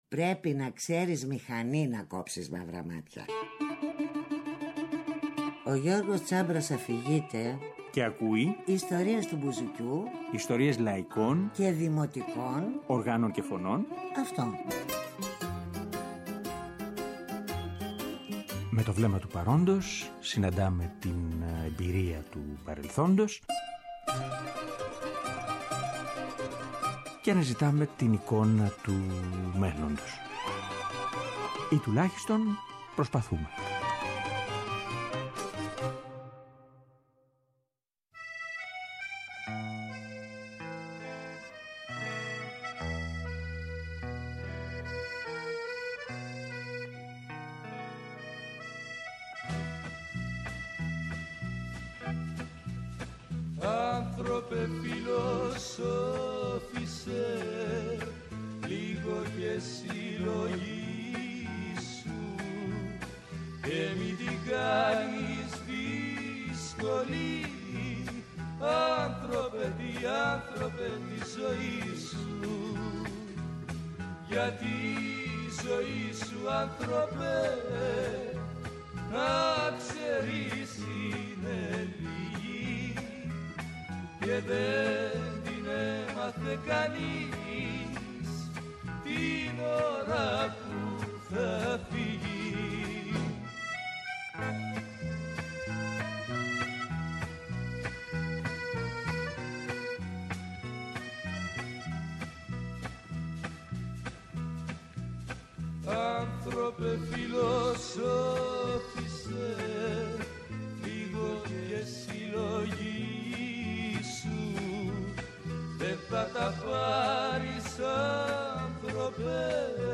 Αφιερώματα Μουσική